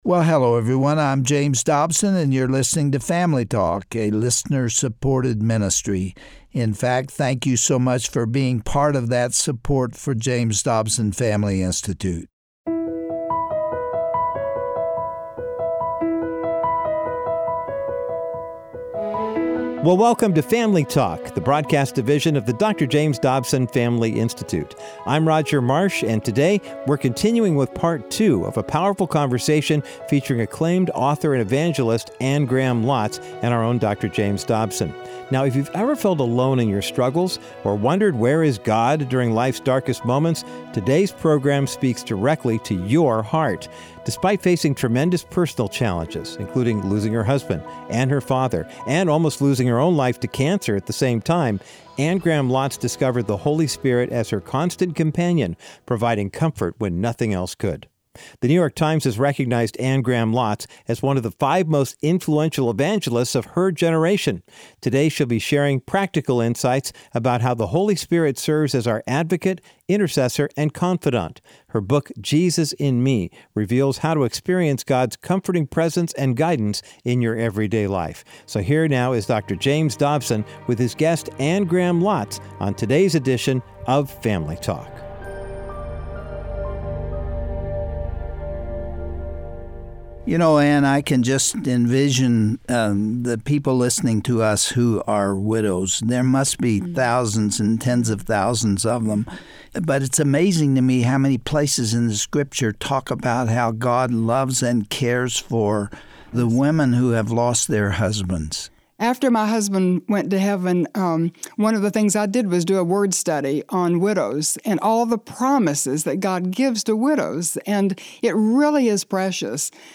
On today’s edition of Family Talk, Dr. James Dobson continues his conversation with Anne Graham Lotz to explore the Holy Spirit as our constant companion.